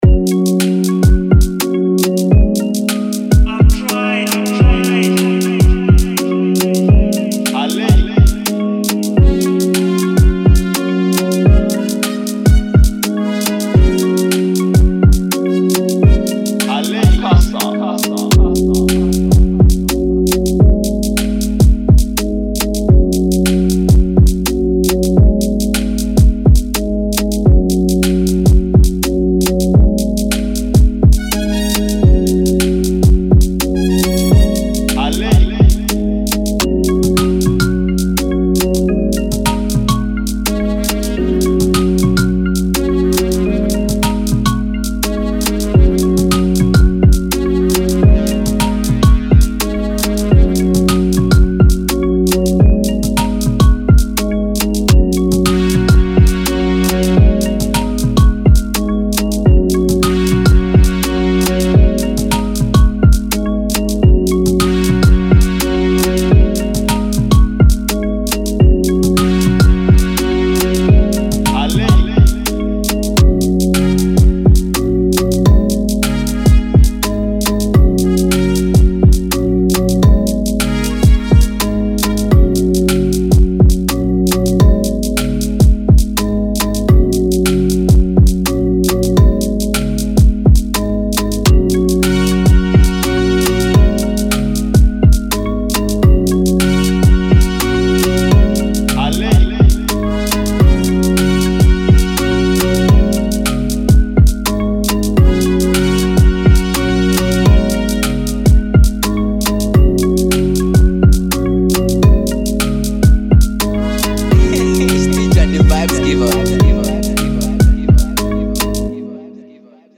remake beat instrumental